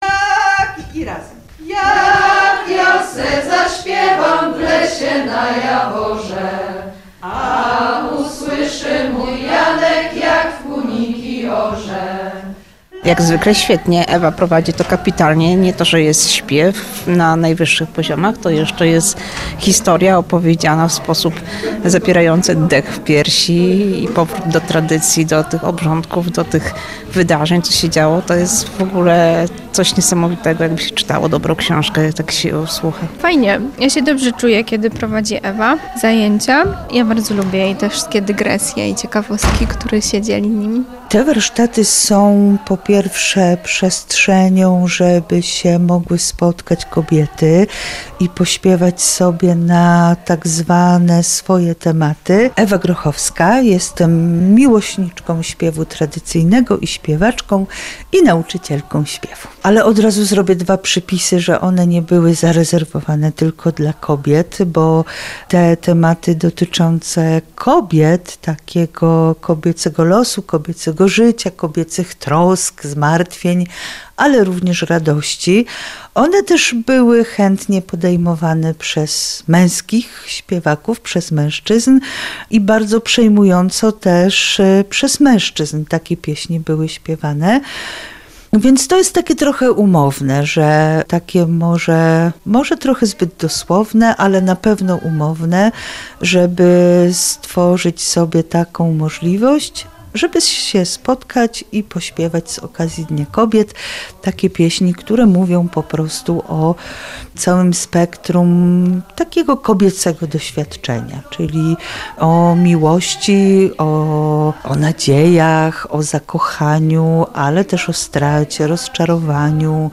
„Pieśni kobiet". Warsztaty śpiewu tradycyjnego w Lublinie